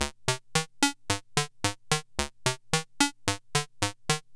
70s Random 110-C#.wav